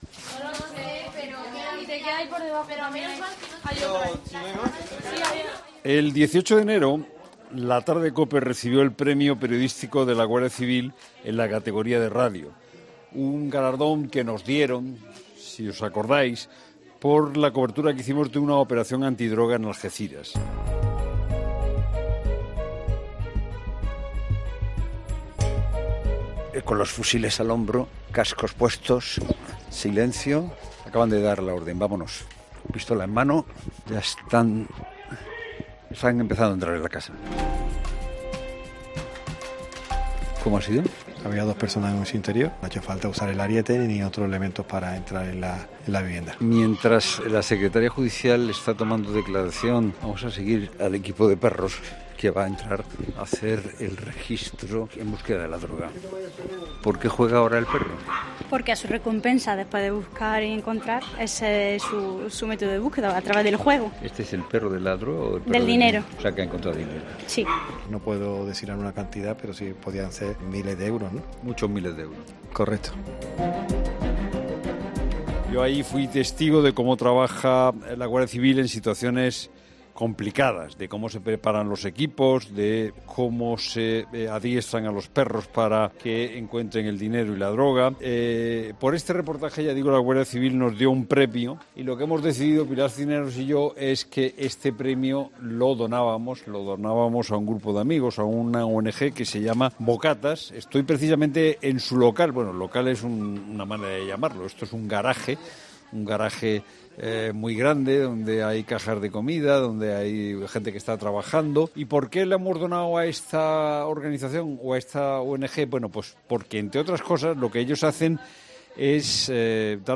Se trata de un garaje, con multitud de cajas de comida y con una cocina en donde se preparan platos y menús diversos para llevar a las personas más desfavorecidas.